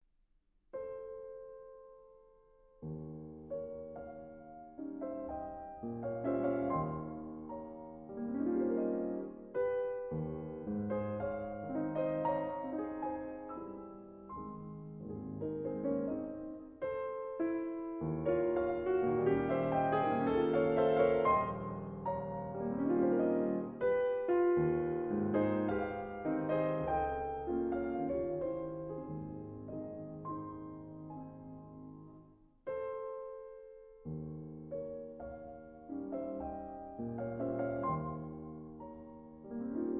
Allegro vivo – Legato e cantabile